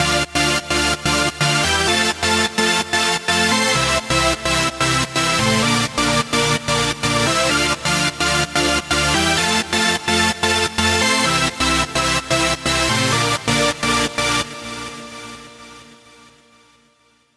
Dieser entstandene Ton kann synthetisch klingen:
synthetisch.mp3